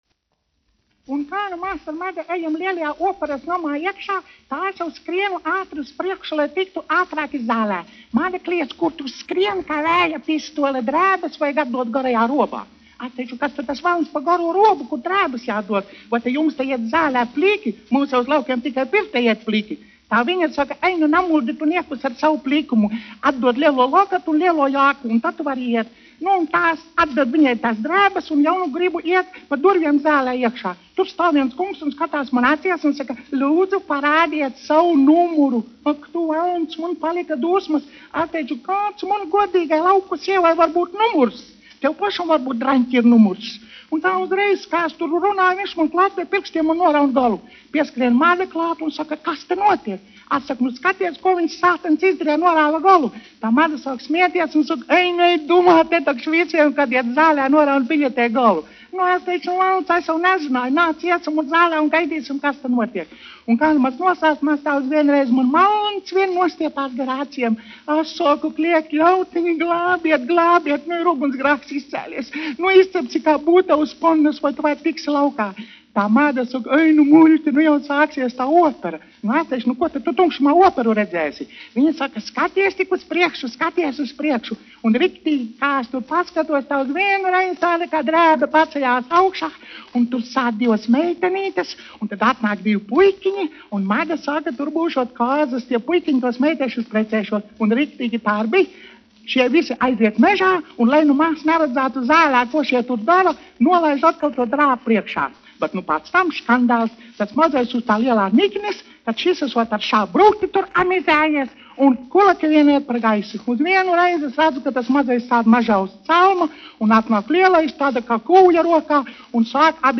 Stiebriņmāte operā : humoristisks nostāsts
1 skpl. : analogs, 78 apgr/min, mono ; 25 cm
Skaņuplate